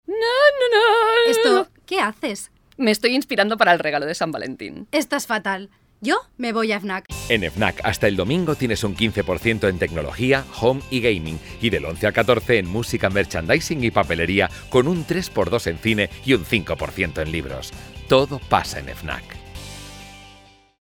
spanish voice over actress with my own studio documentary animation corporate animation dubbing advertising song e-learning e-learning audio book audio guide audio guide audio description speed seriousness commitment
kastilisch
Sprechprobe: Werbung (Muttersprache):